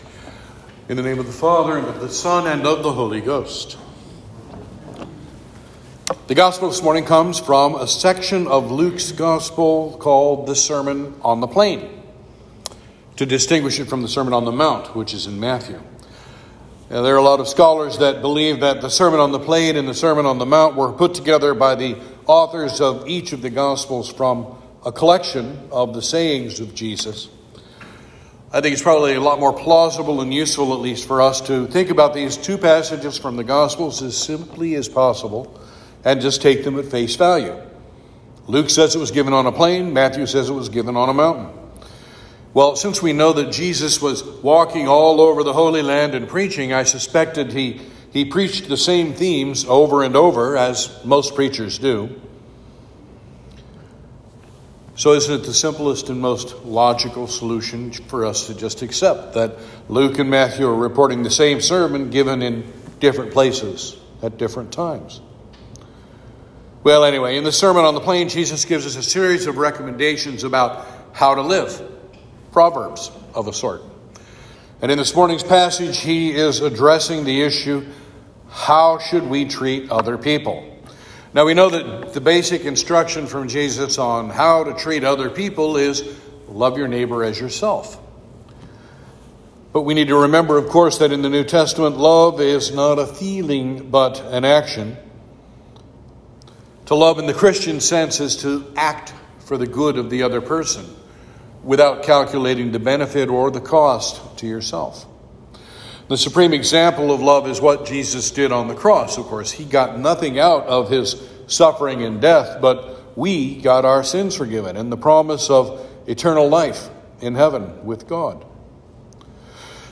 Saint George Sermons Sermon for Trinity 4